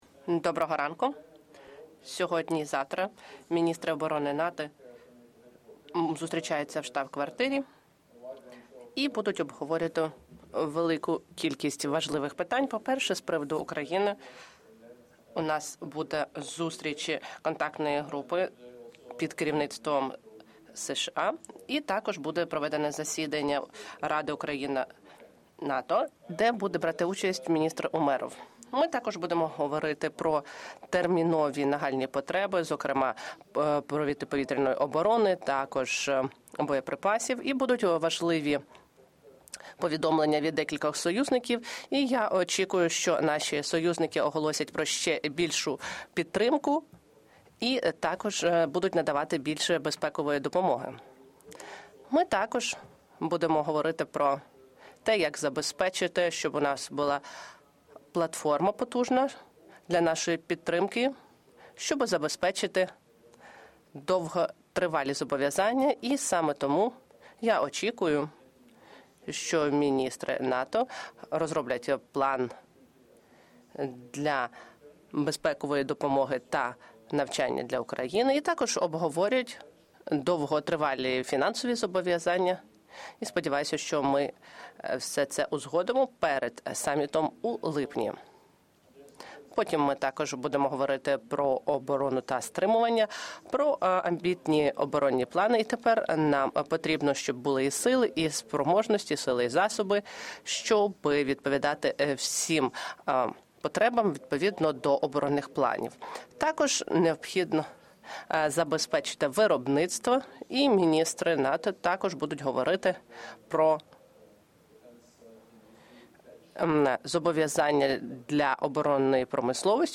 ENGLISH - Press conference by NATO Secretary General Jens Stoltenberg following the second day of the meetings of NATO Defence Ministers
Secretary General’s press conference